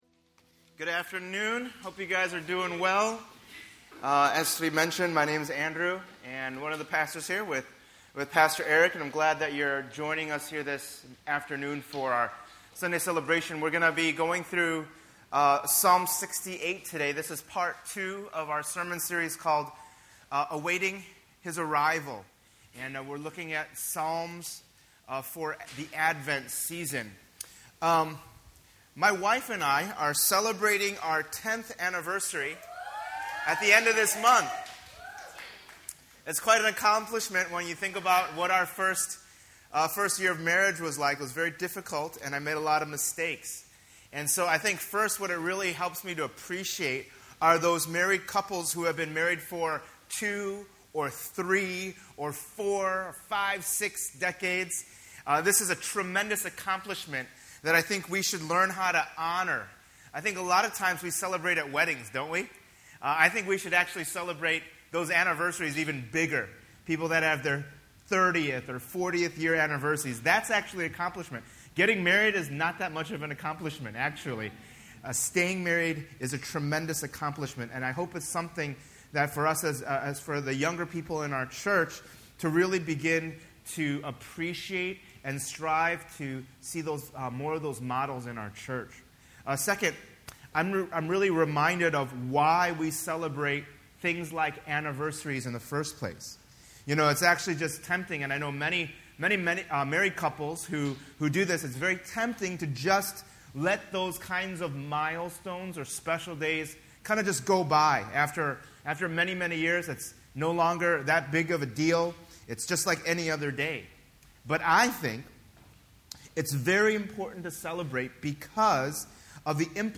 The Advent season is to commemorate Christ's arrival into our broken world. Throughout this sermon series, we’ll look into the Psalms and see how God’s people waited for a Savior.